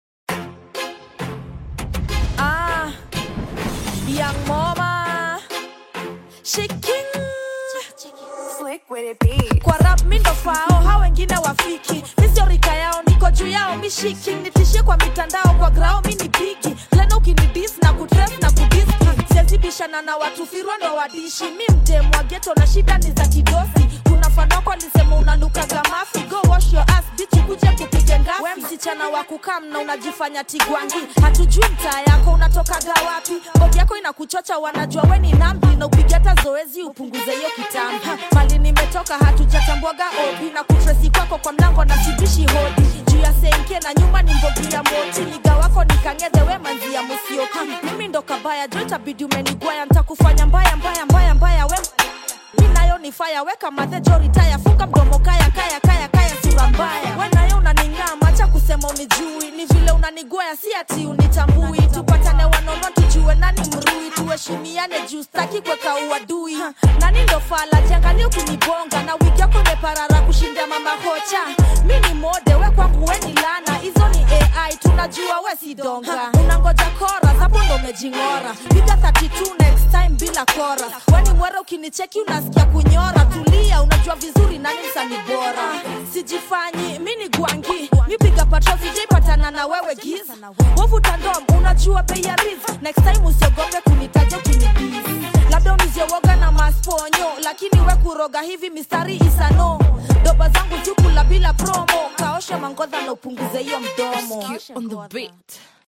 is a fiery Tanzanian Hip-Hop diss track